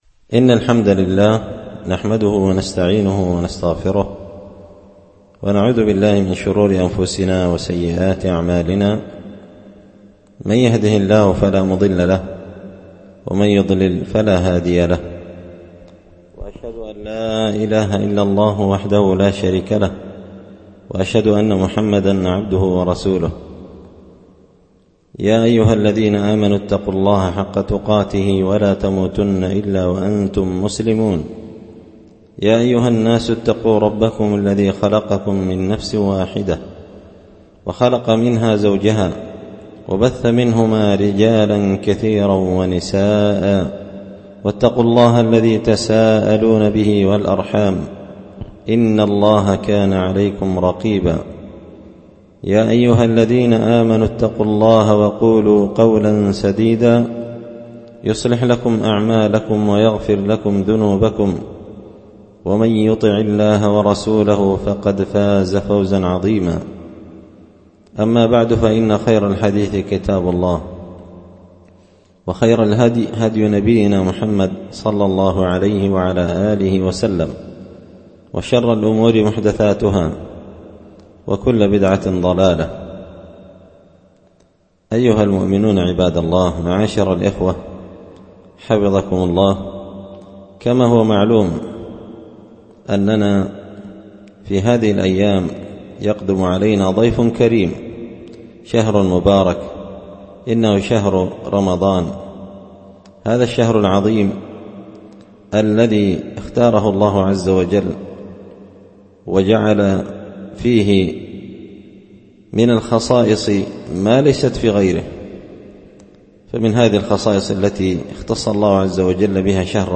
الأحد 27 شعبان 1444 هــــ | الخطب والمحاضرات والكلمات | شارك بتعليقك | 19 المشاهدات
ألقيت هذه المحاضرة بدار الحـديـث السلفية بمـسجـد الفـرقـان قشن-المهرة-اليمن